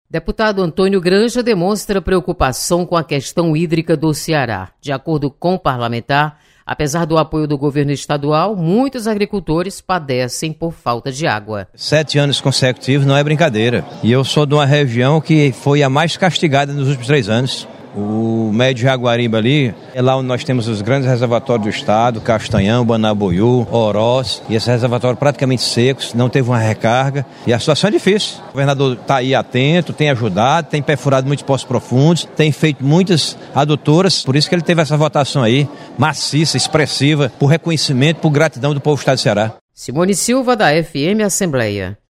Deputado Antônio Granja mostra preocupação com desabastecimento de água. Repórter